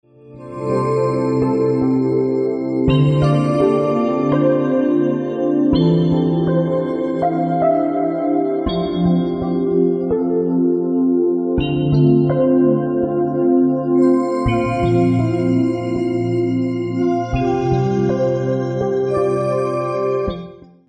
Przepiękna harmonijna muzyka do masażu.